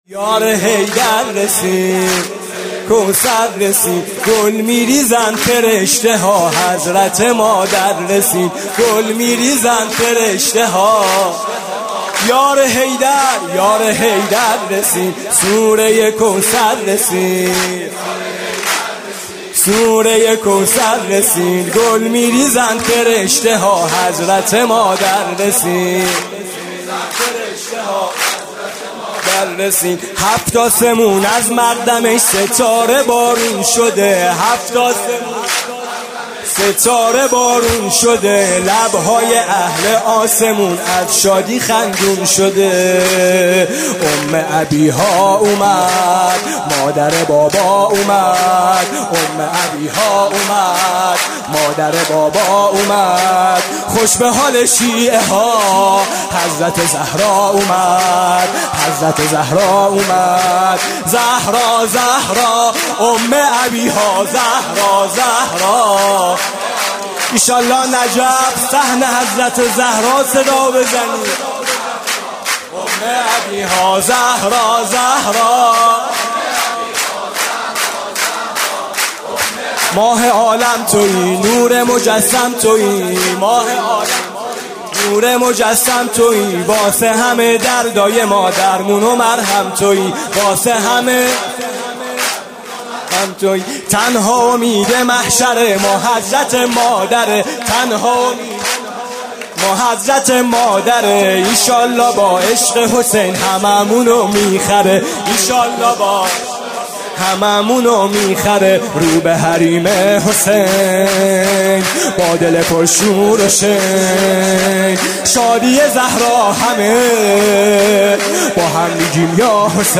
سرود: یار حیدر رسید، سوره کوثر رسید